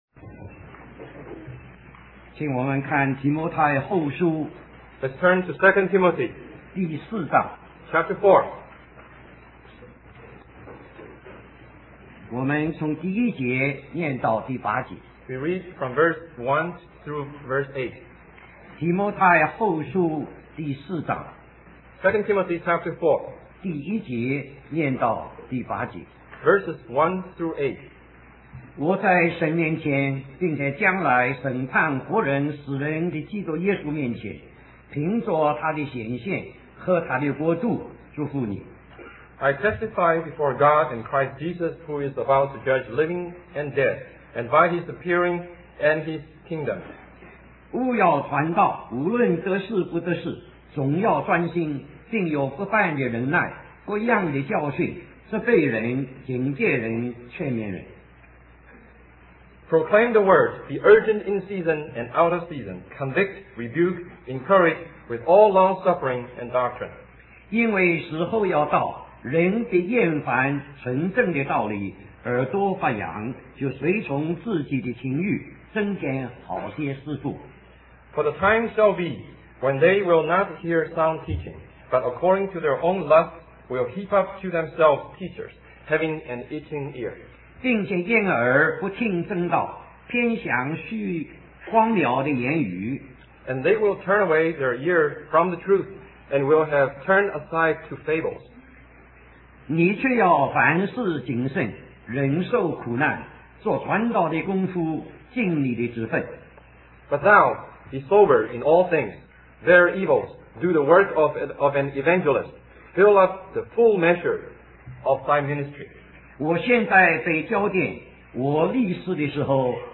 Conference at Bible Institute of Los Angeles